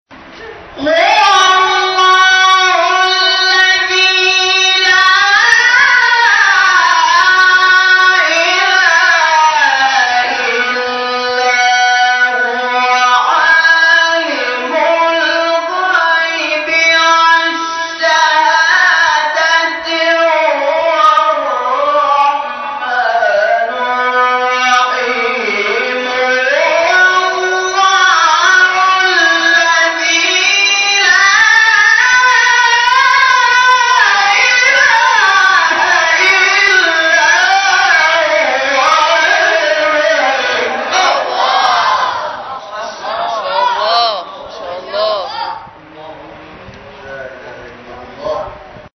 این فرازها به ترتیب در مقام‌های؛ نهاوند، عزام، بیات، حجاز و رست اجرا شده است.